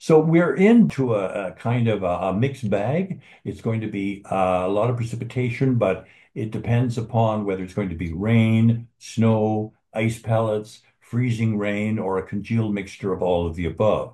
David Phillips, Senior Climatologist for Environment Canada explains that the Upper Ottawa Valley can expect plenty of whiplash as the forecast shifts this weekend.